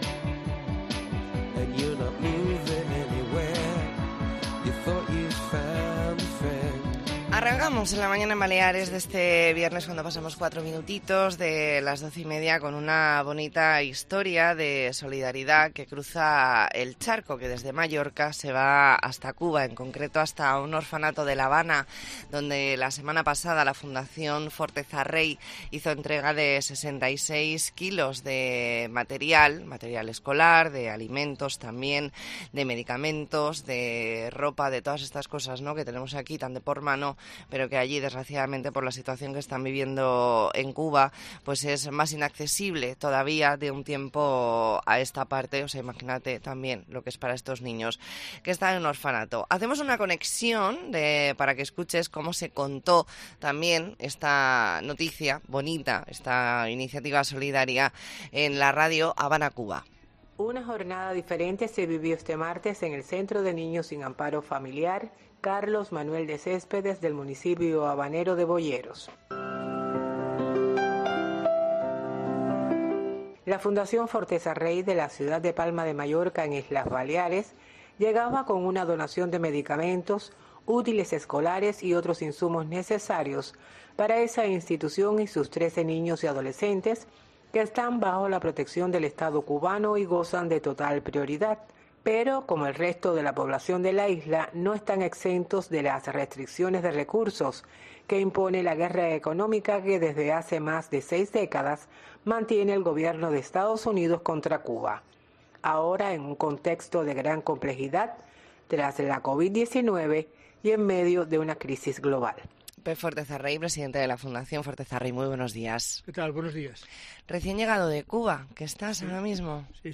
E ntrevista en La Mañana en COPE Más Mallorca, viernes 13 de octubre de 2023.